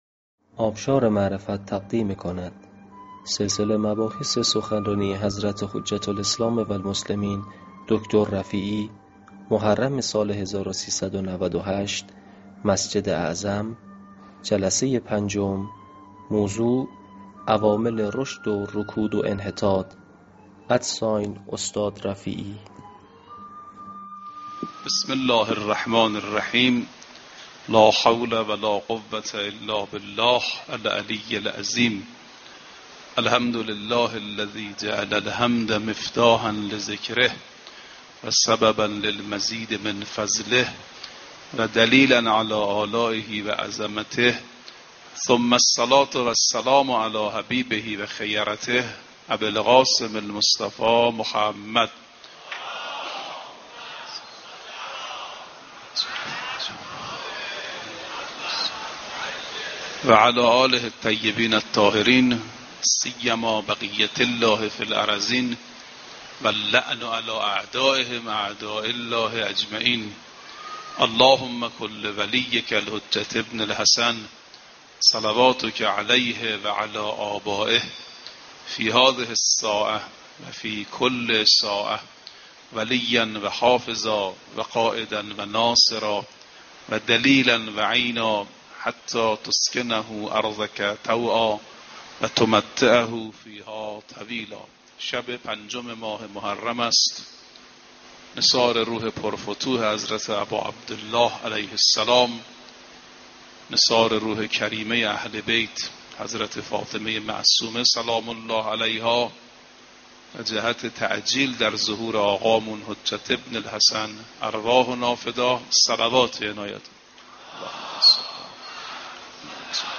در مراسم عزاداری سید و سالار شهیدان امام‌ حسین (ع) در مسجد اعظم قم